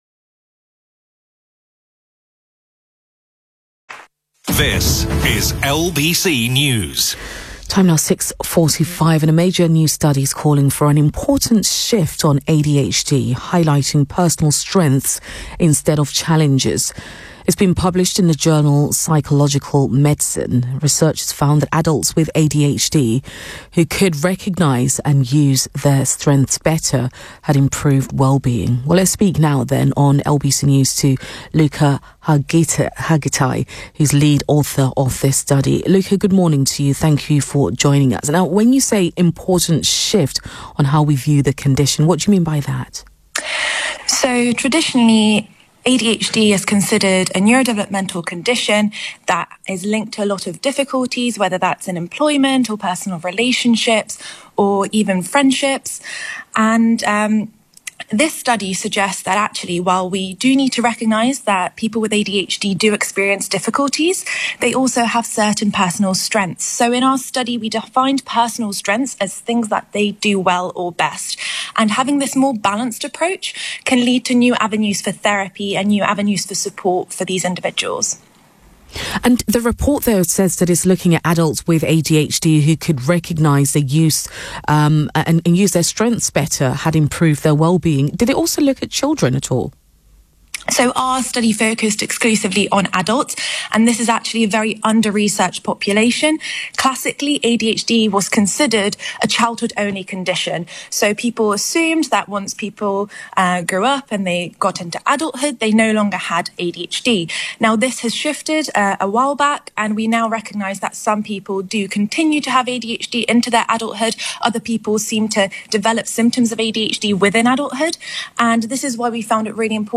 LBC news radio interview